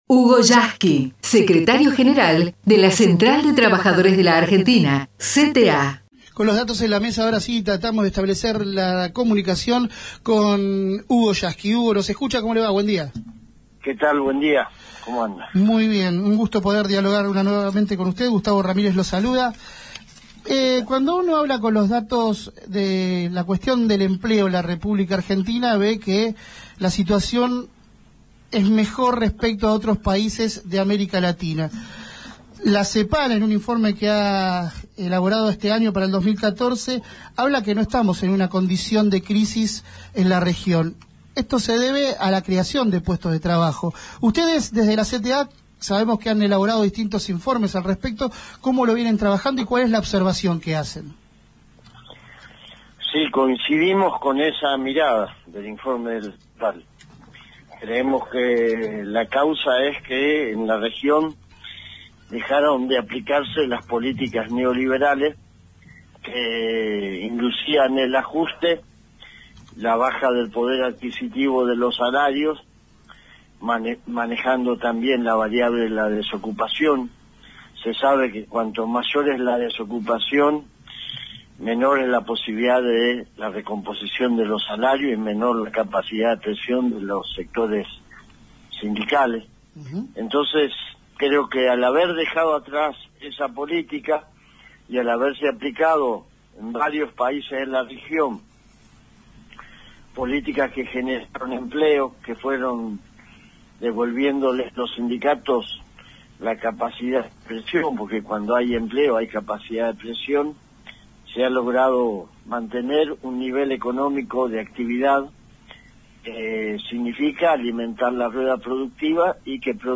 HUGO YASKY (entrevista) RADIO GRÁFICA
Secretario General de la Central de Trabajadores de la Argentina